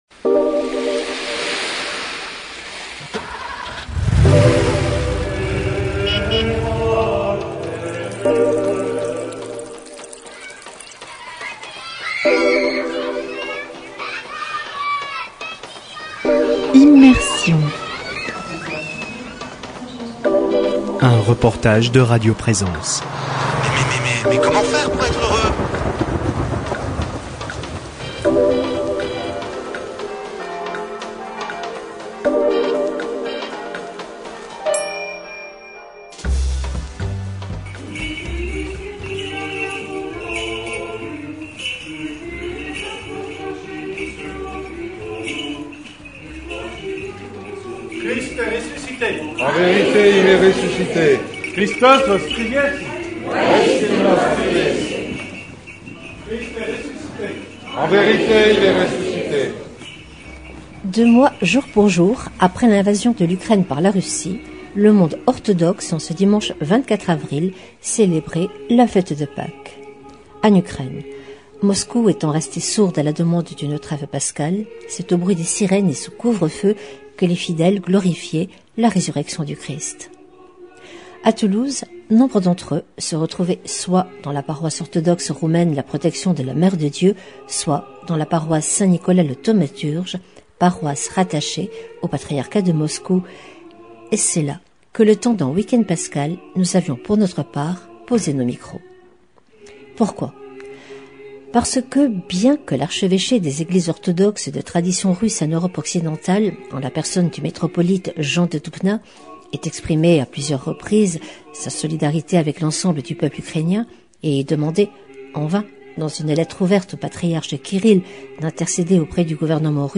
Deux mois jour pour jour après l’invasion de l’Ukraine par la Russie, les Eglises orthodoxes, en ce dimanche 24 avril, célébraient la fête de Pâques. . A Toulouse le temps d’un week-end pascal nous posions nos micros dans la paroisse St Nicolas le thaumaturge, paroisse rattachée au Patriarcat de Moscou...